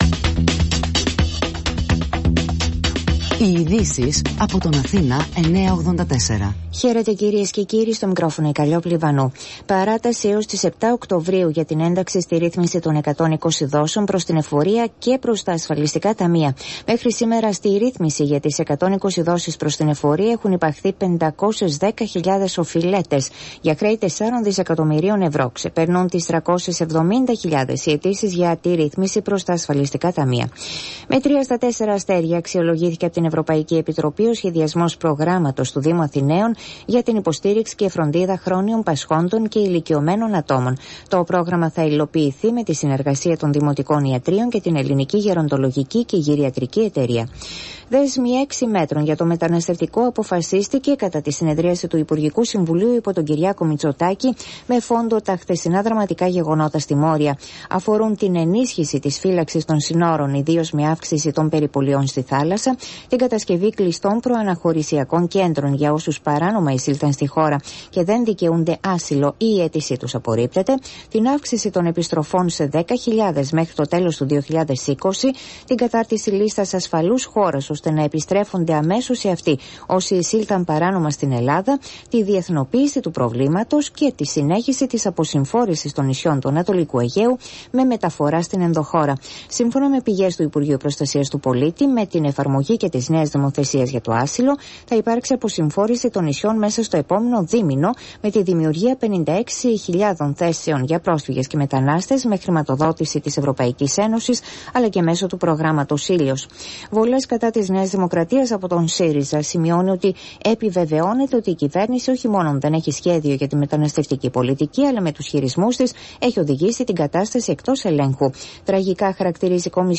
Δελτίο Ειδήσεων στις 17:00